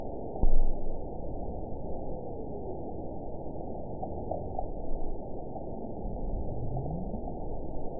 event 922046 date 12/26/24 time 01:02:49 GMT (11 months, 1 week ago) score 9.45 location TSS-AB03 detected by nrw target species NRW annotations +NRW Spectrogram: Frequency (kHz) vs. Time (s) audio not available .wav